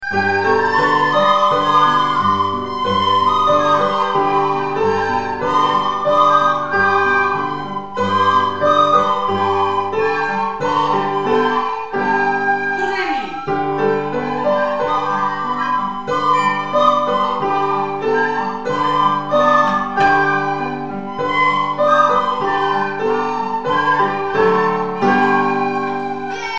Download link Els alumnes de 6è.B interpreten la cançó: Abril, maig, juny amb la flauta dolça i acompanyament de piano.
abril-amb-flauta-6b.mp3